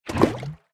fill_lava3.ogg